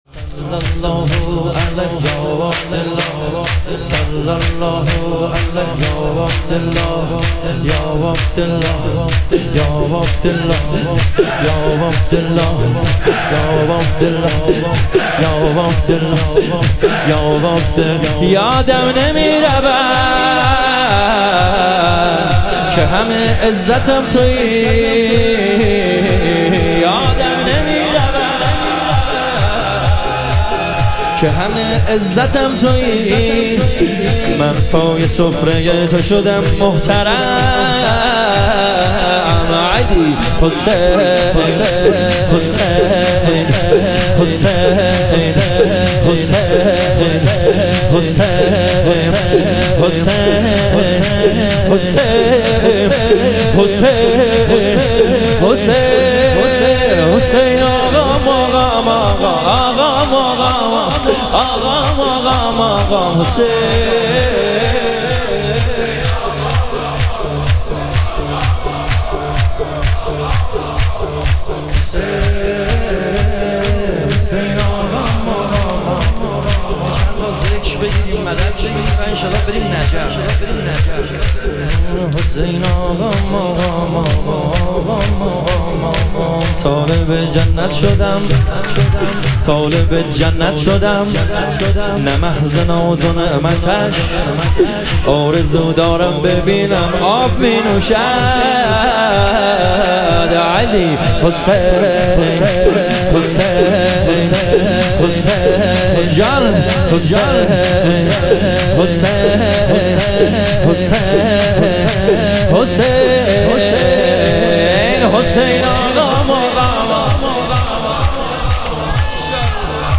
شهادت امام کاظم علیه السلام - شور